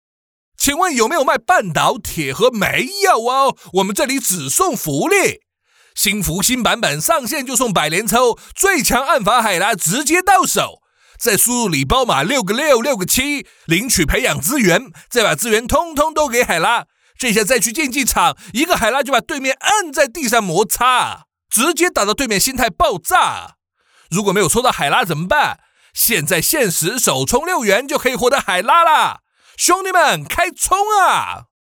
【信息流】半岛铁盒 台湾腔
【信息流】半岛铁盒  台湾腔.mp3